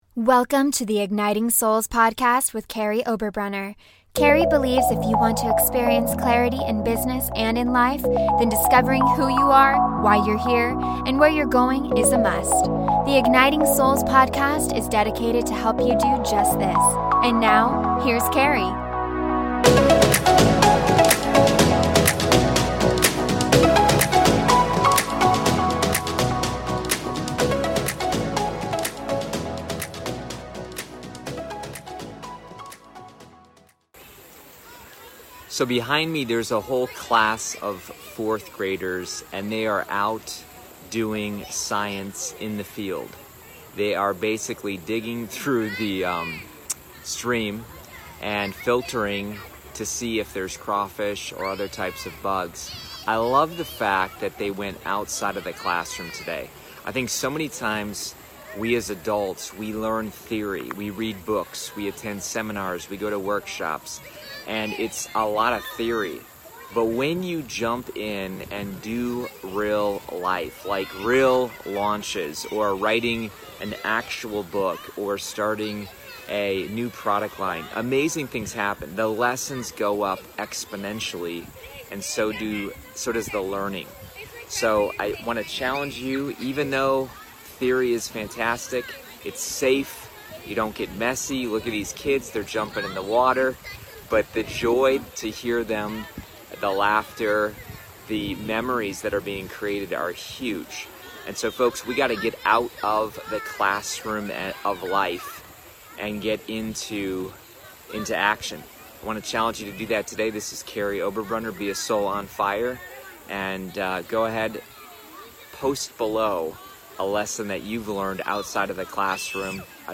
Behind me is a class of fourth graders doing science in the field.